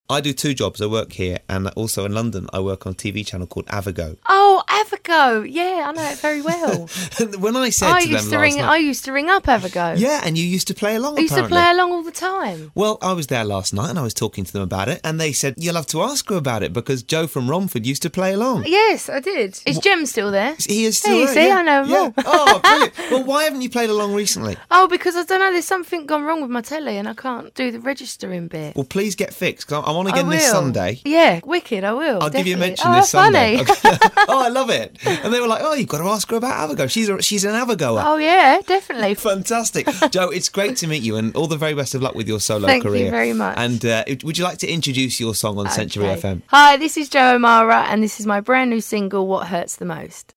centuryfm_clip_web.mp3